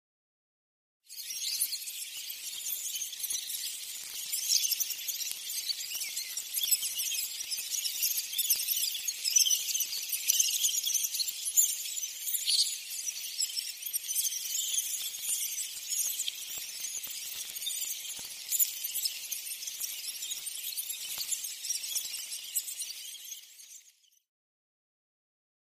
Rats Ambience 1 - Many Rats Sewage, Twittering, Fast Moving